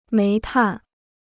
煤炭 (méi tàn) Dicionário de Chinês gratuito